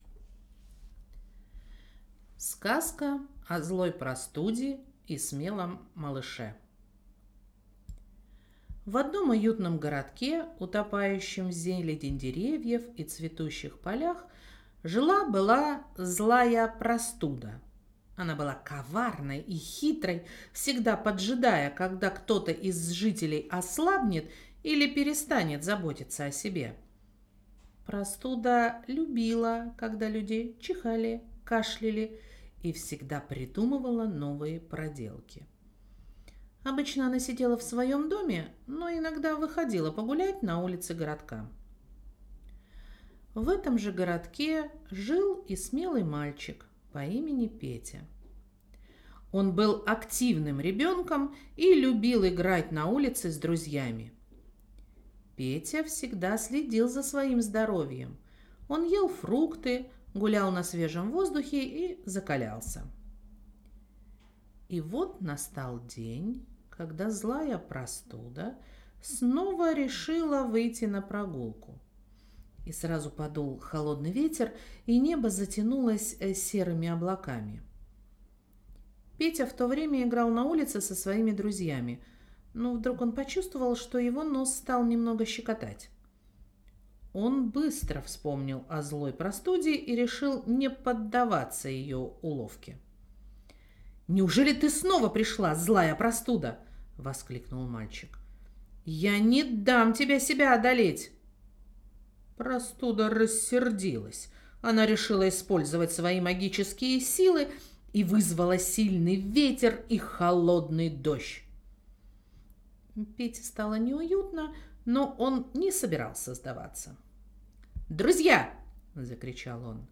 Детская сказка "О злой простуде и смелом малыше"
skazka-o-zloj-prostude-i-smelom-malyishe-online-audio-convertercom.mp3